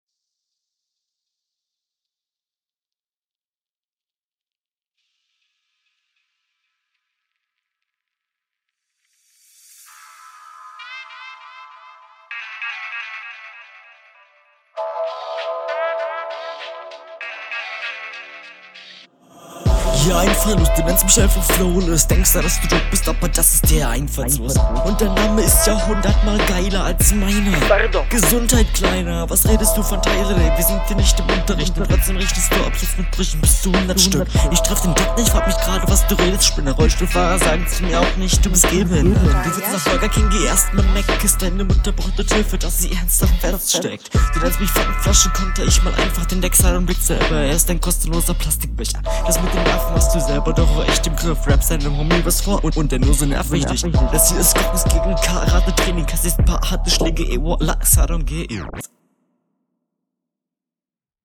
das größte problem ist hier ganz klar der mix. deine stimme kommt mal stärker und …
7 sec stille am Anfang okay.